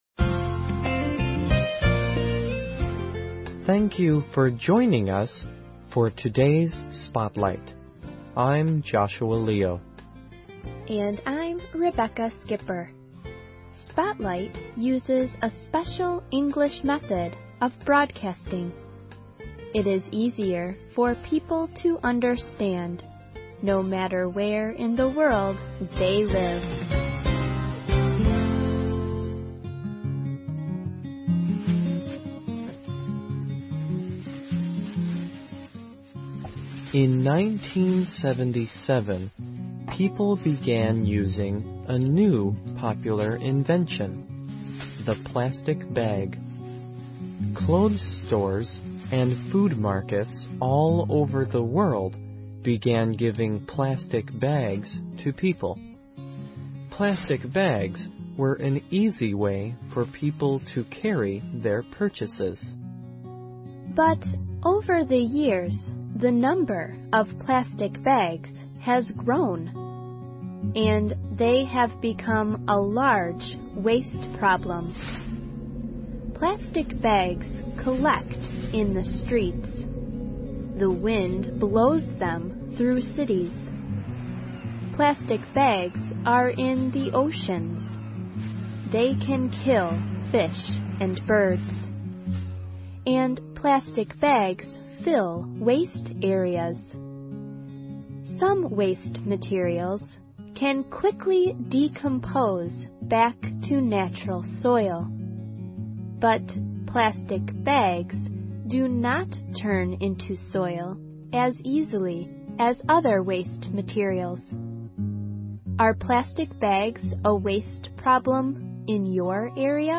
环球慢速英语 第100期:塑料袋废物(1)